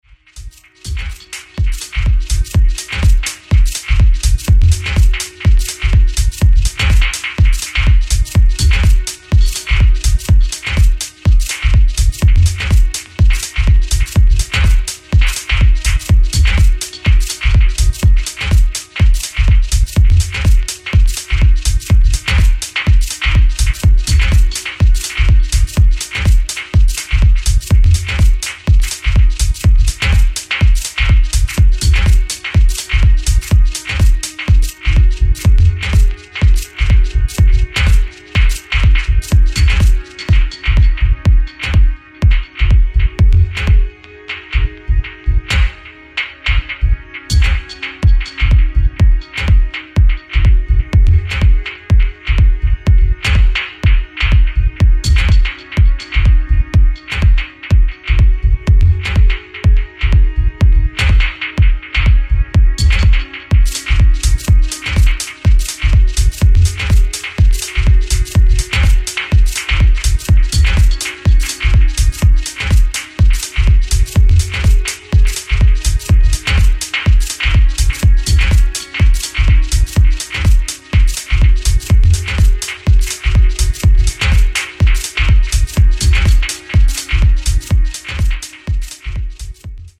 featuring three originals from the UK Techno stalwart.